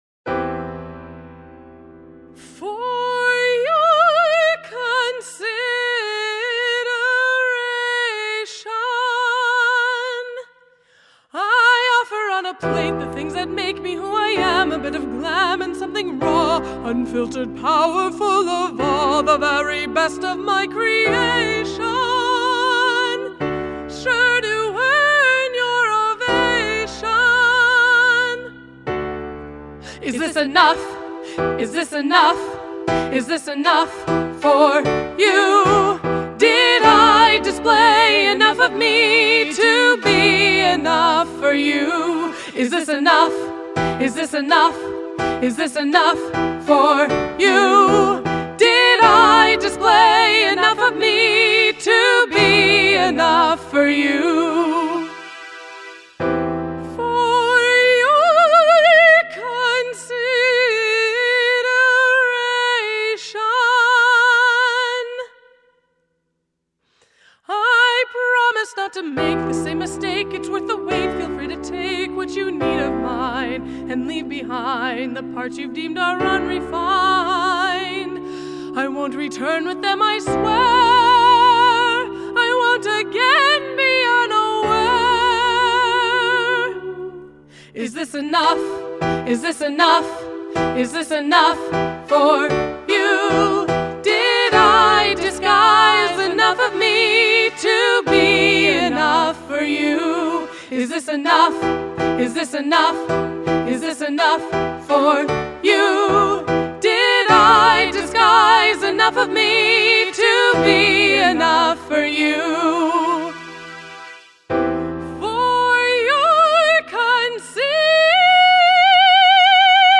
The challenge is met with a piano and a synth*